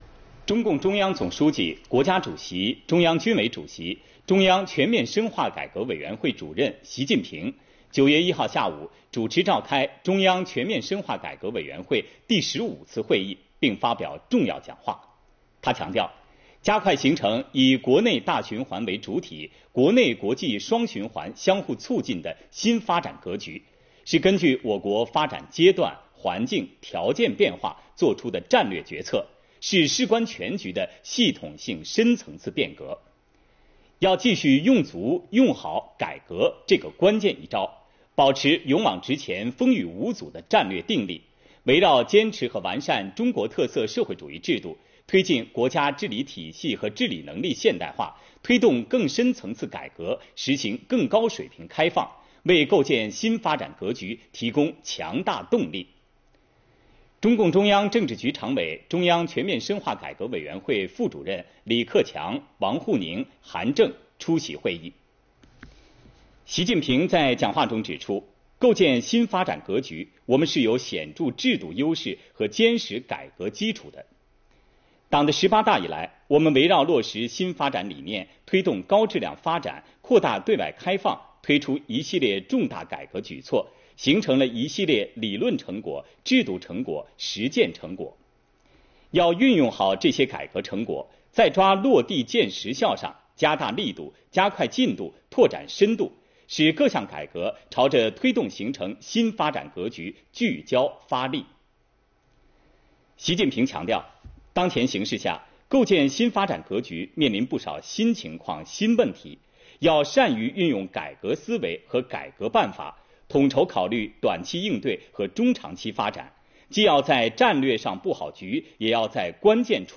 视频来源：央视《新闻联播》